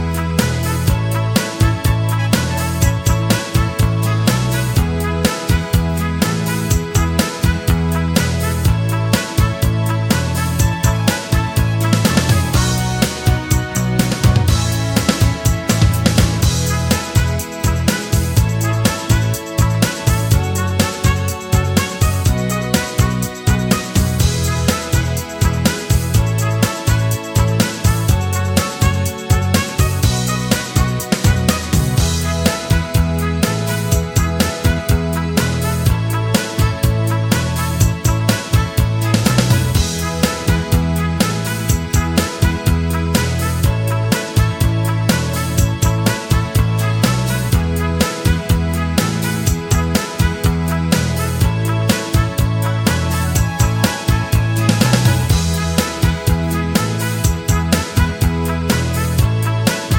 no Backing Vocals Indie / Alternative 3:47 Buy £1.50